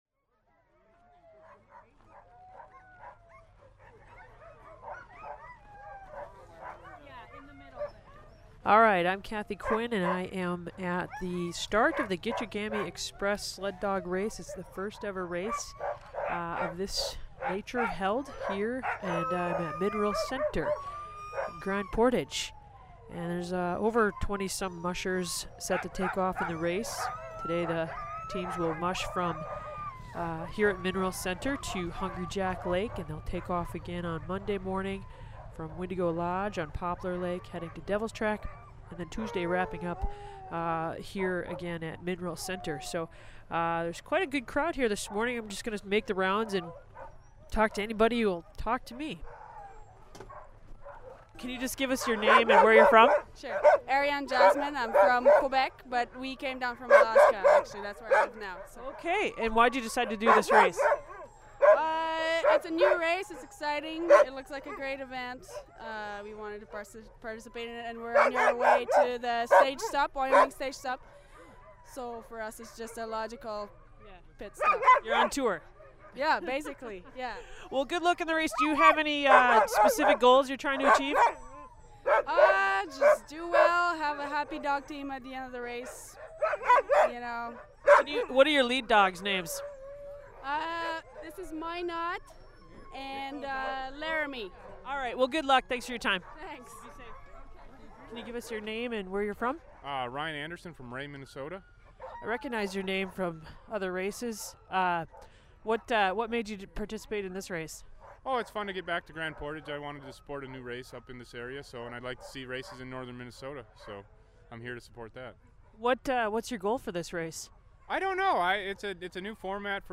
On scene at the Gichigami Express